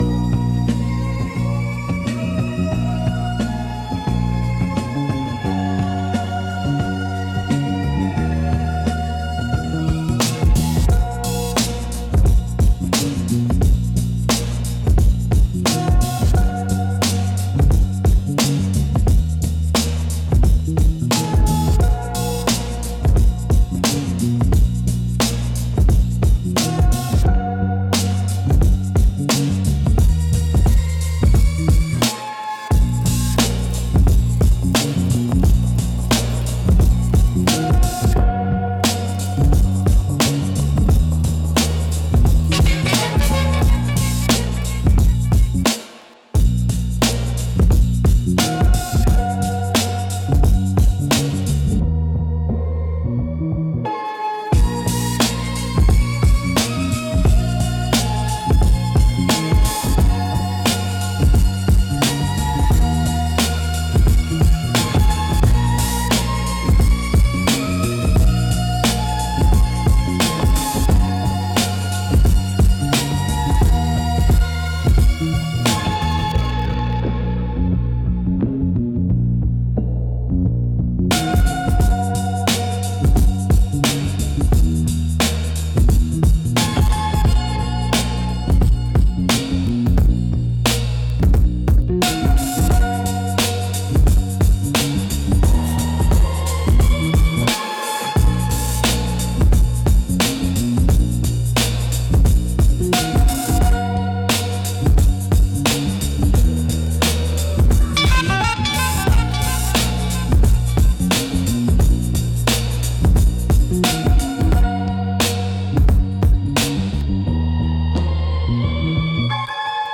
Instrumental - The Question I Can’t Ask - 3.28